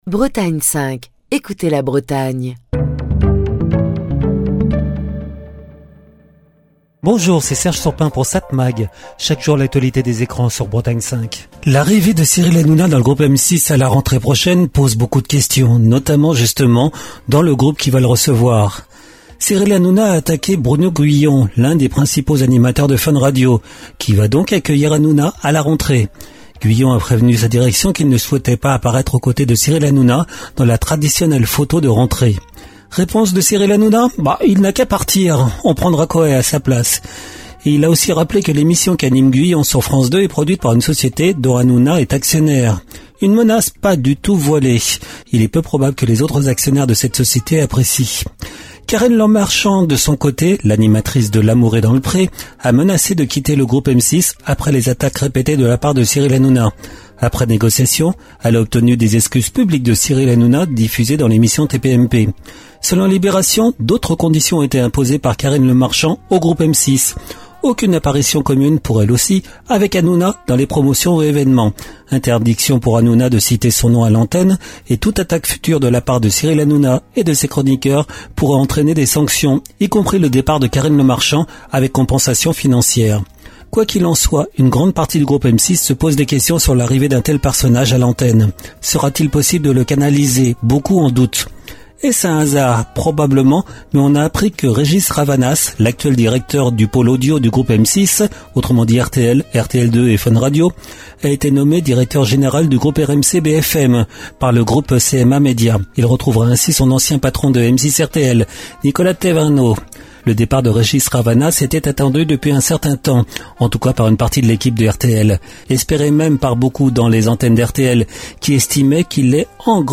Chronique du 17 mars 2025. L’arrivée de Cyril Hanouna dans le groupe M6 à la rentrée prochaine soulève de nombreuses questions, y compris au sein même du groupe qui s’apprête à l’accueillir.